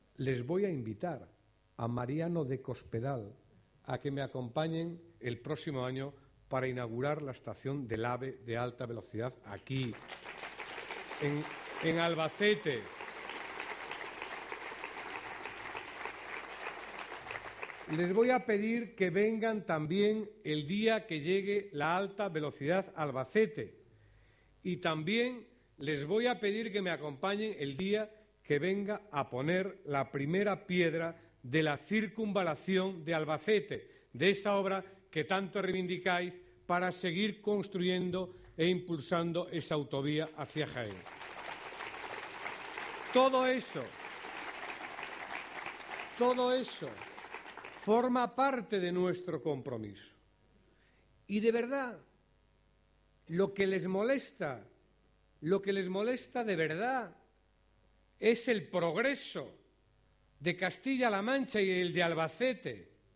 Acto Público de los socialistas de Albacete con el Vicesecretario General y ministro de Fomento